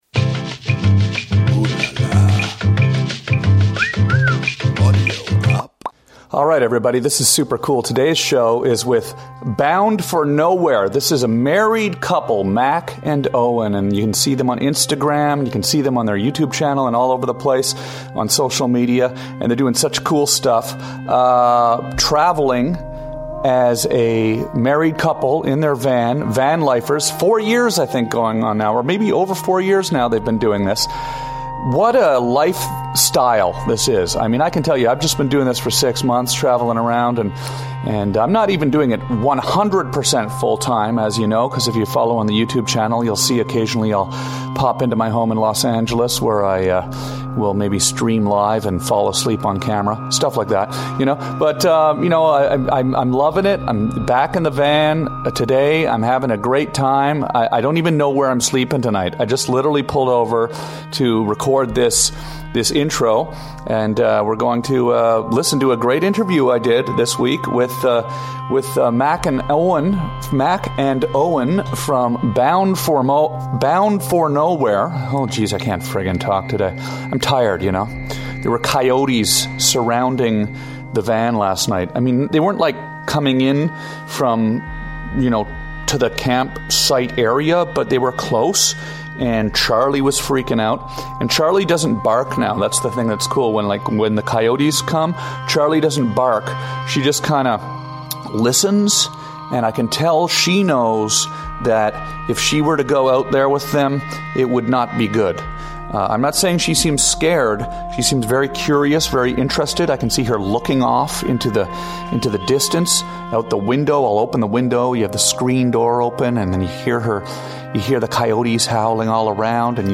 This interview is the epitome of Van Life. Everything you need to know about how to live on the road.